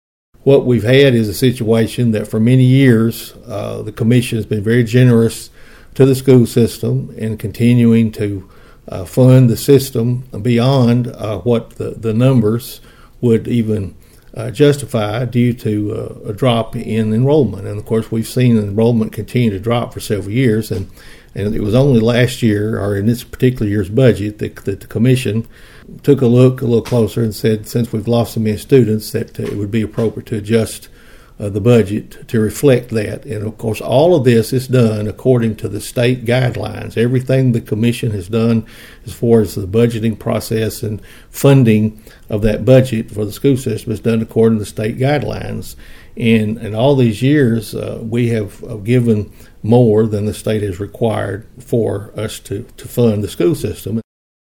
Following this week’s Budget Committee meeting, which included a vote for no additional school funding, Mayor Carr talked with Thunderbolt News about the issue.(AUDIO)